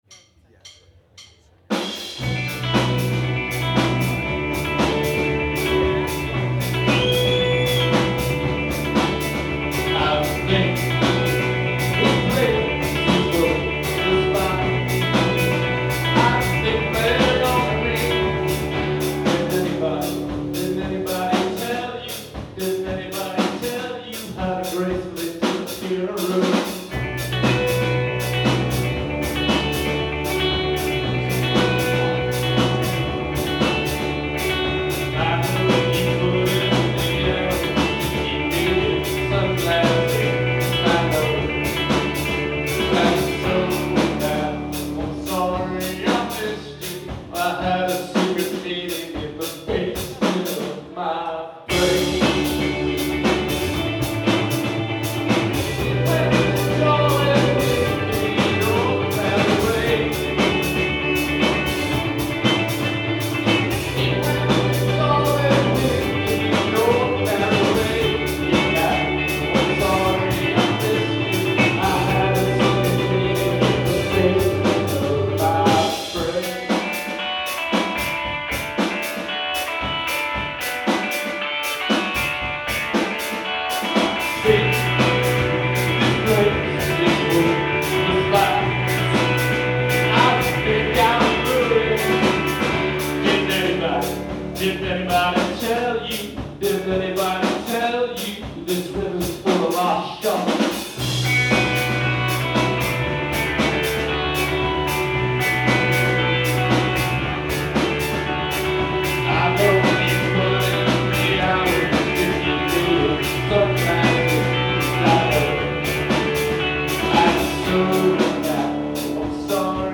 live at TT the Bears